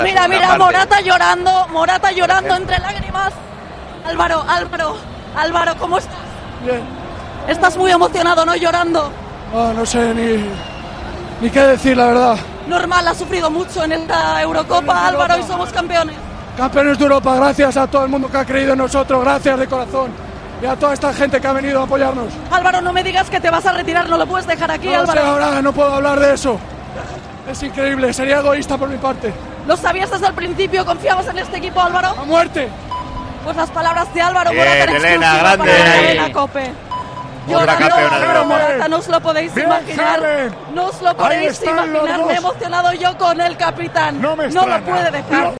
La selección española conquistó su cuarto título continental y el equipo del combinado nacional comentó: "No sé ni que decir. Gracias a todo el mundo que ha creído en nosotros".